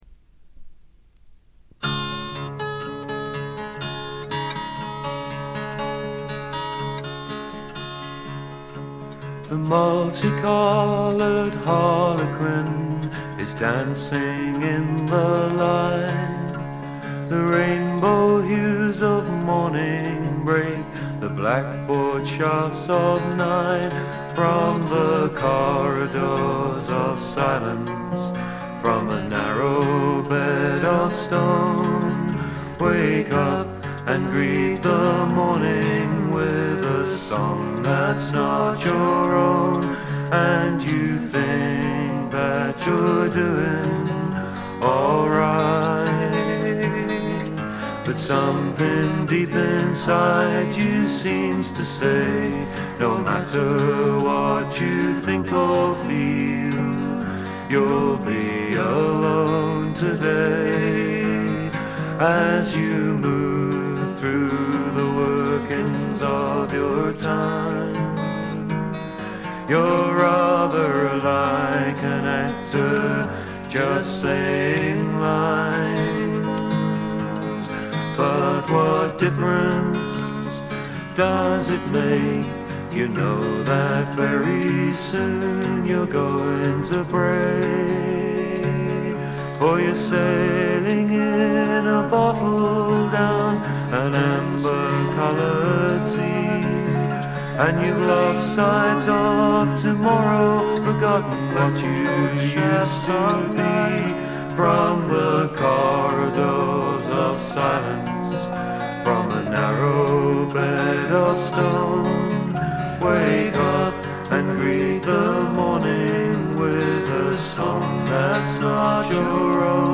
部分視聴ですが、ほとんどノイズ感無し。
全編を通じて飾り気のないシンプルな演奏で「木漏れ日フォーク」ならぬ「黄昏フォーク」といったおもむきですかね。
試聴曲は現品からの取り込み音源です。